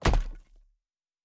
Stone 01.wav